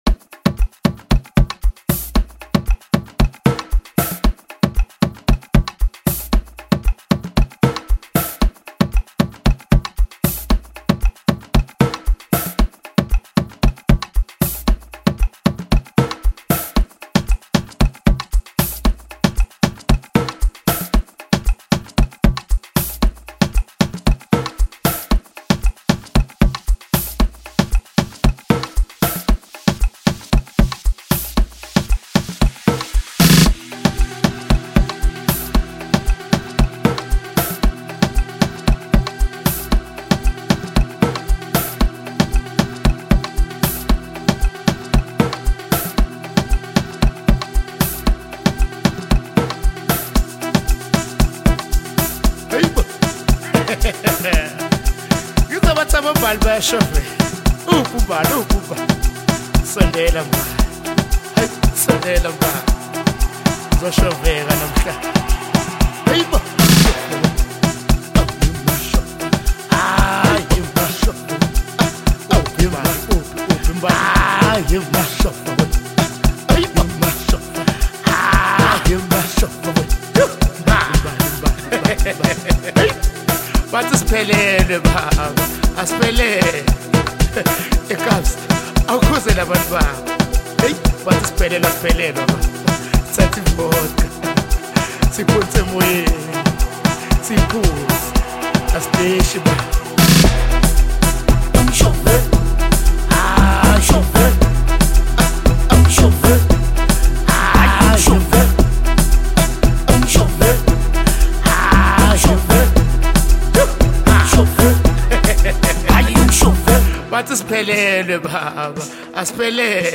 • Genre: Afro-House